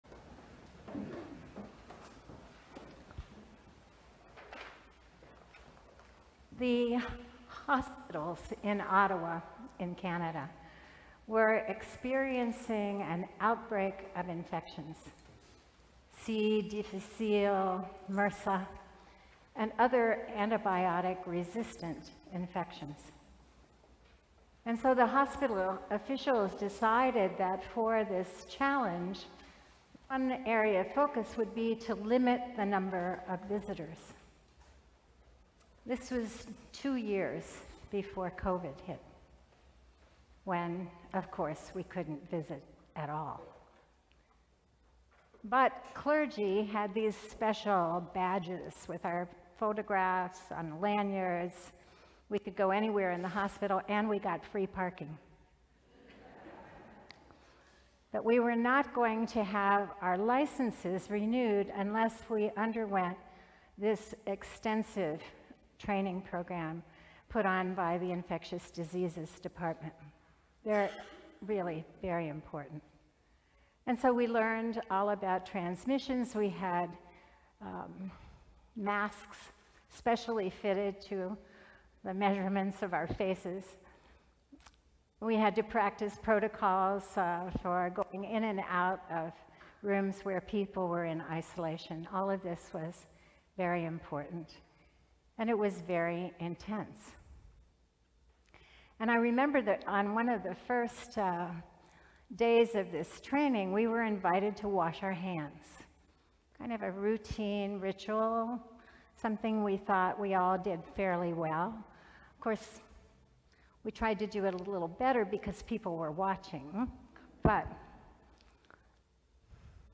The Fifteenth Sunday after Pentecost We invite you to join us in worship at St. […]
Sermons from St. John's Cathedral